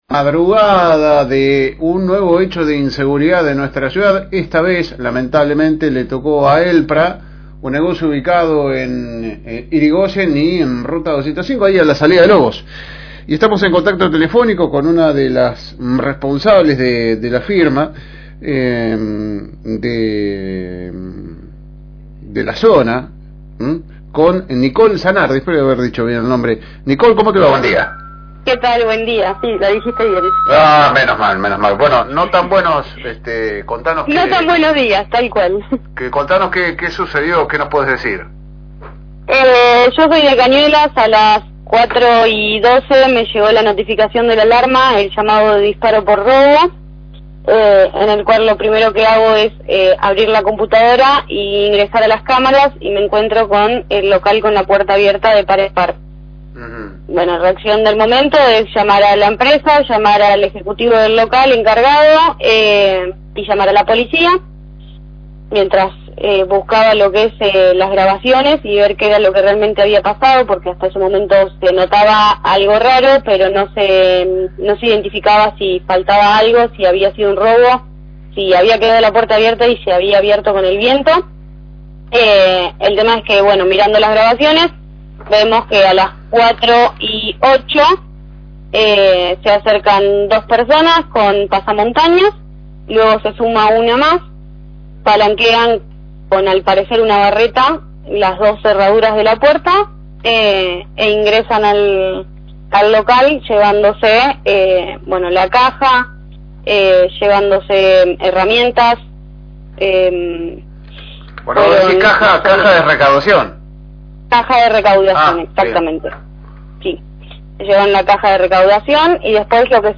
Charlamos con la delegada zonal